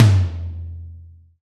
Index of /90_sSampleCDs/Roland - Rhythm Section/TOM_Real Toms 1/TOM_Dry Toms 1
TOM AC.TOM0A.wav